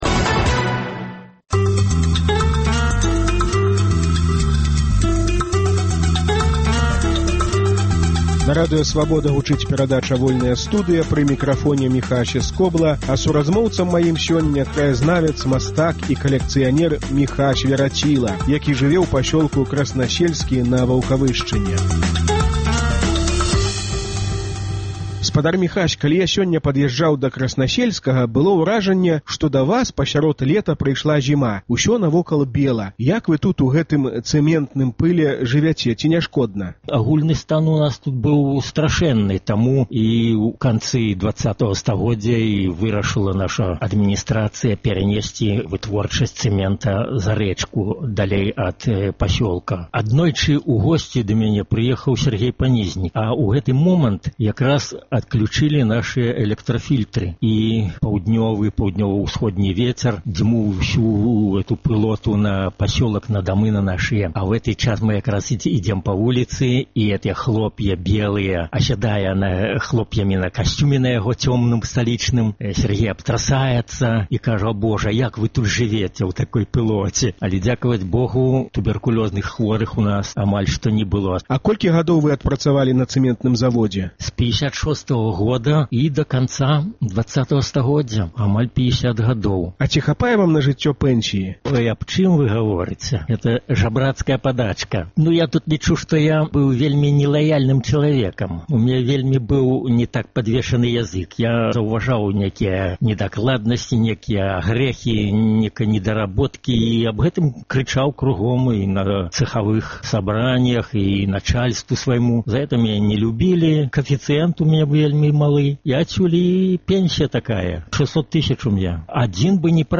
Колькі каштаваў аўтограф Льва Сапегі на Ваўкавыскім кірмашы? Гутарка